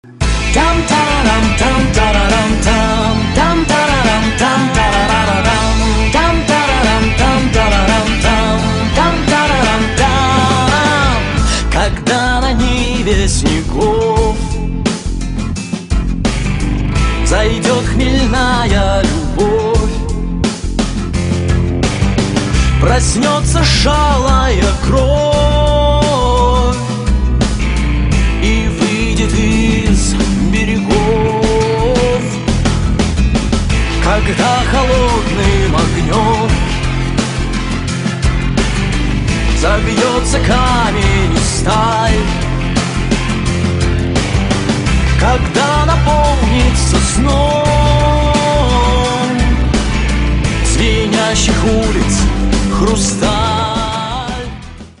• Качество: 128, Stereo
мужской вокал
OST
бодрые
легкий рок